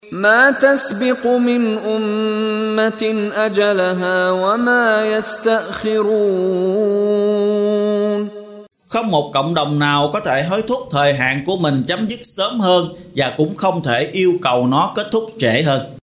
Đọc ý nghĩa nội dung chương Al-Muminun bằng tiếng Việt có đính kèm giọng xướng đọc Qur’an